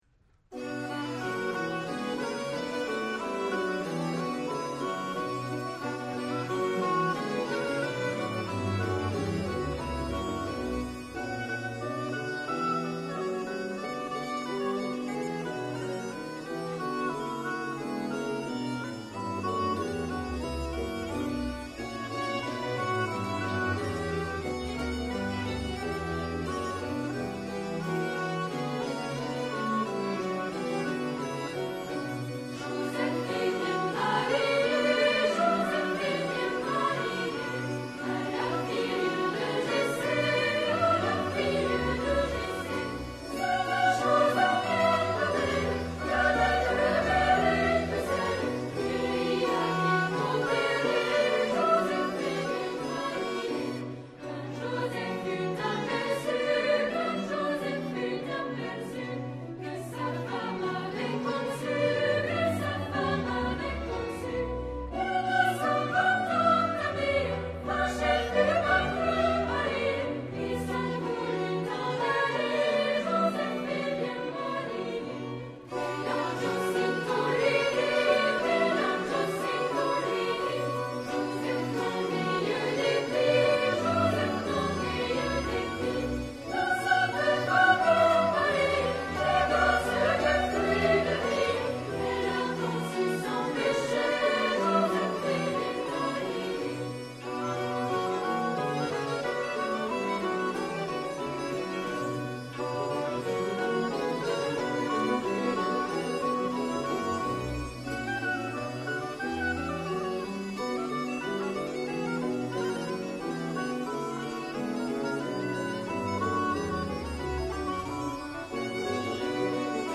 1 maîtrise et de 7 à 9 instrumentistes
flûtes, musette, direction
baryton, tympanon, percussions